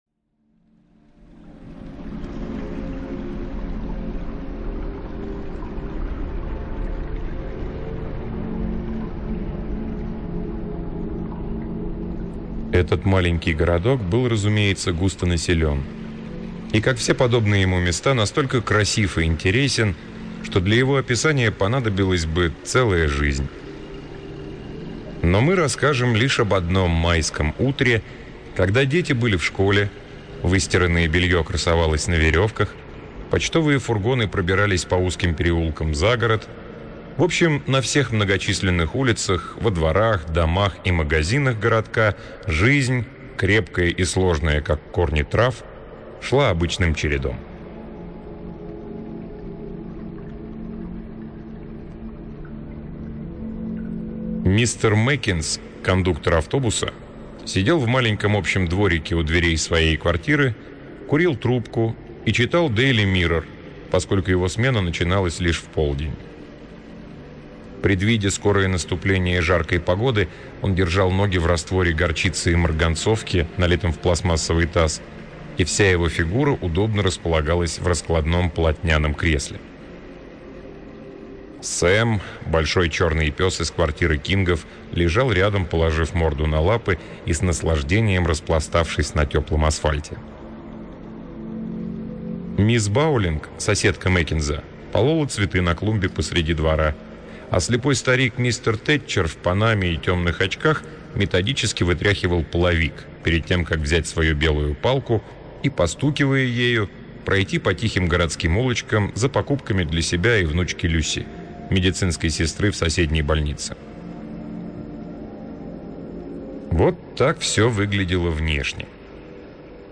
Аудиокнига Джоан Айкен — Пять зеленых лун